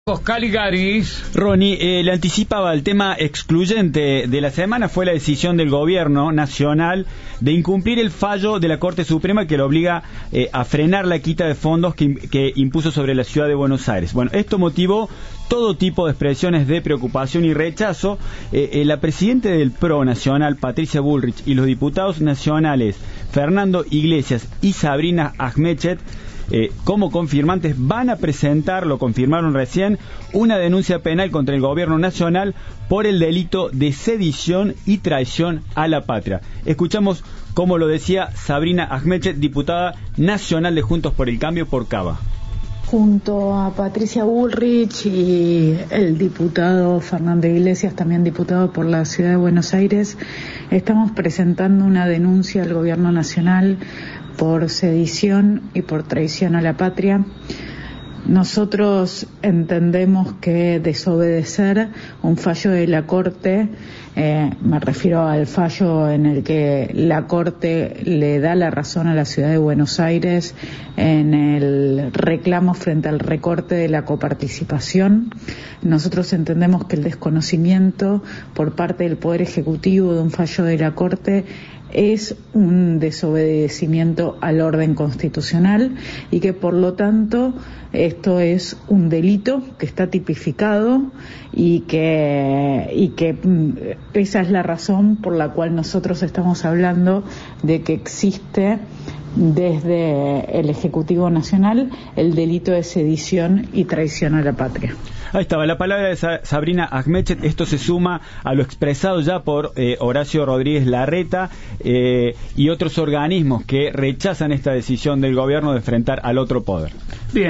La diputada Sabrina Ajmechet explicó por qué denunciarán penalmente al Presidente